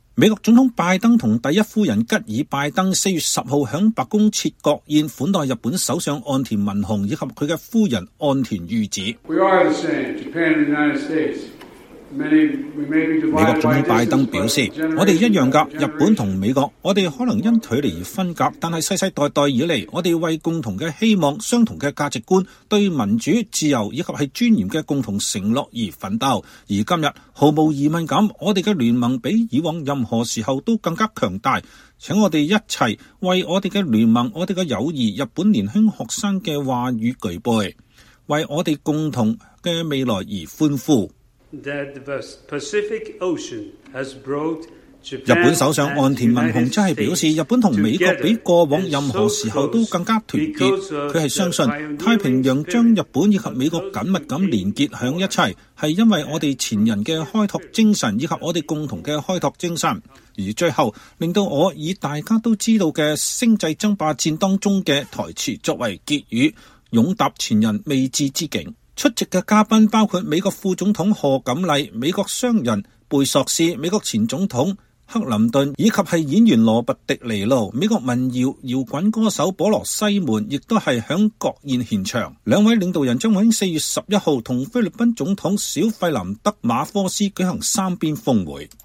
美國總統拜登和第一夫人吉爾拜登4月10日在白宮設國宴款待日本首相岸田文雄和夫人岸田裕子。
喬拜登 美國總統
岸田文雄日本首相